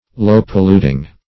Meaning of low-polluting. low-polluting synonyms, pronunciation, spelling and more from Free Dictionary.